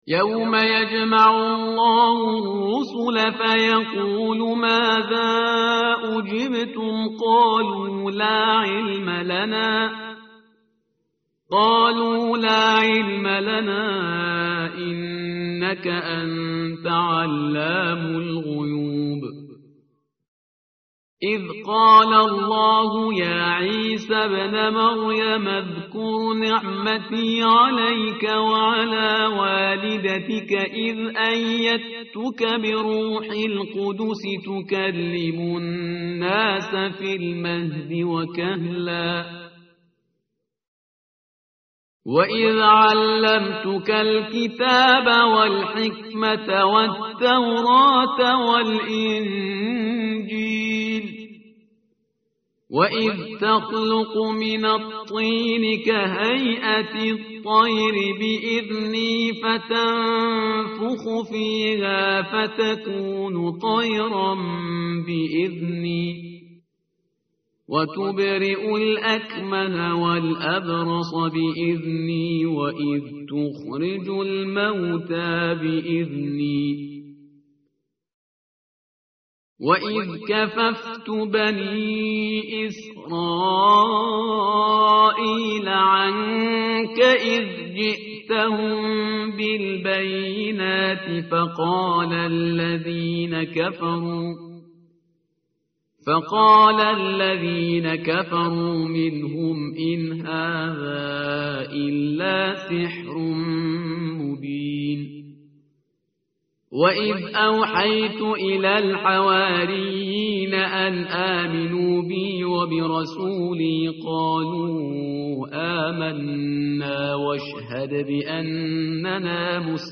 متن قرآن همراه باتلاوت قرآن و ترجمه
tartil_parhizgar_page_126.mp3